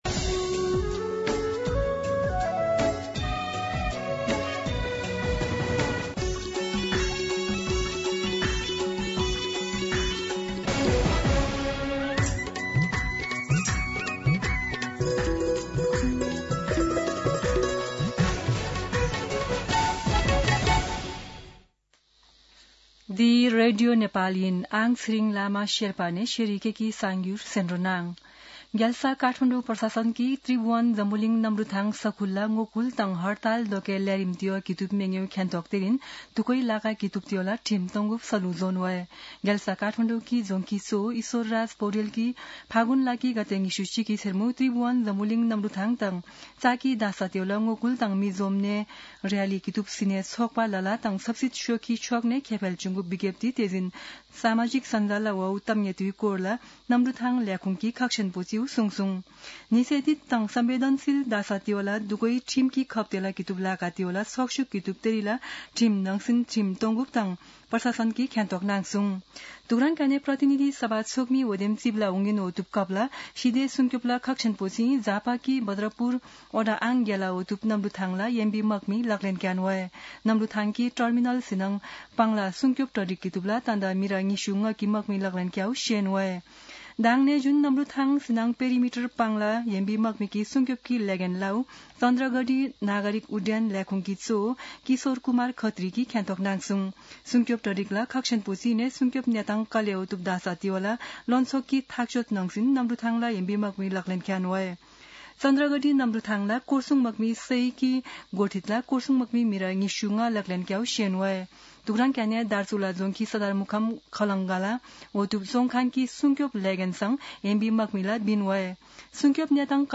शेर्पा भाषाको समाचार : २ फागुन , २०८२
Sherpa-News-02.mp3